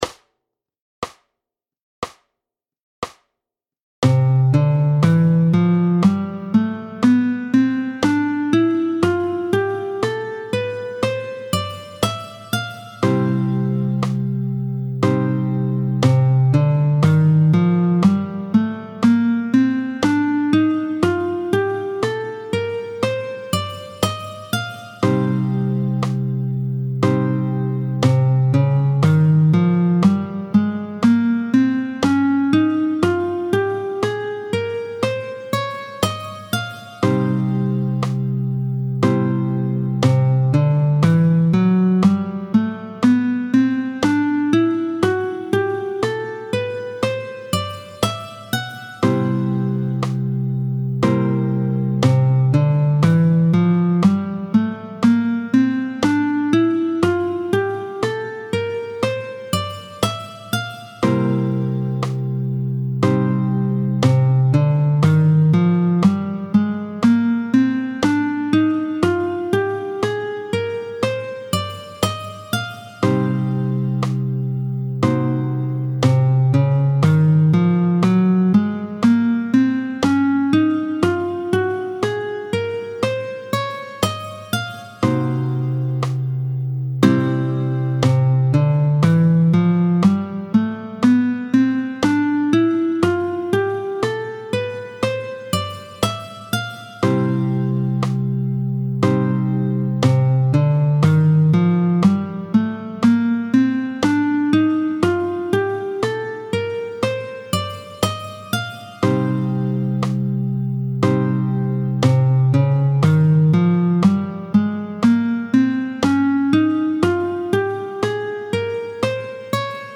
27-08 Les 7 modes de Do, tempo 60